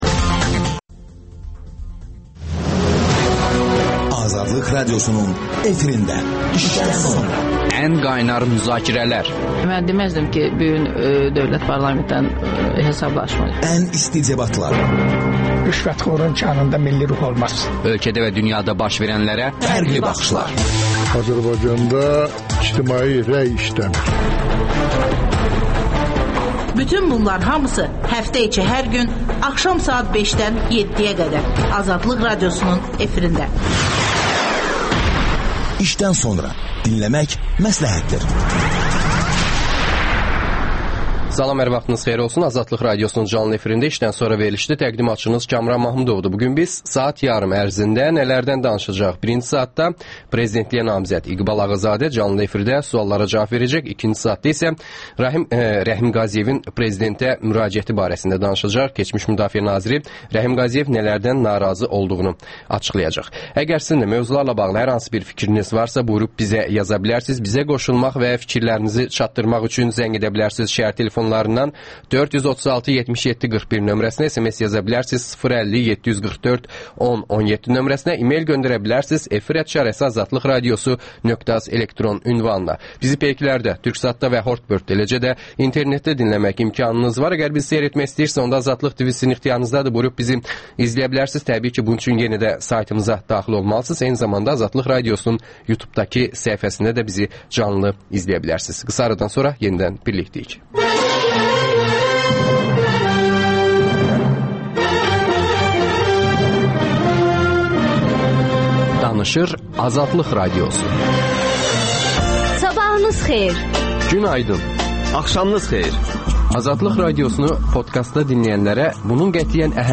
İqbal Ağazadə canlı efirdə suallara cavab verir.